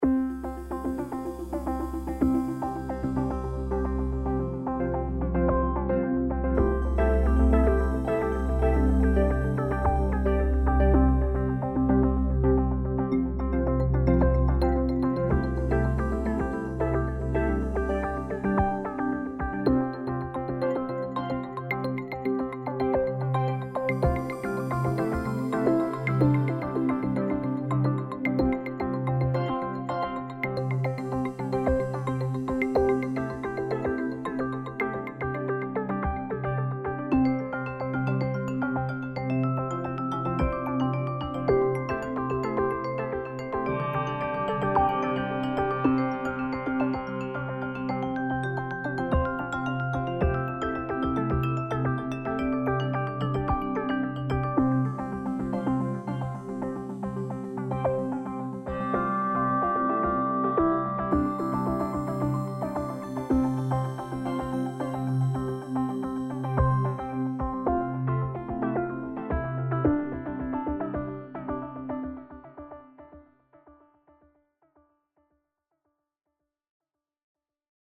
Main menu music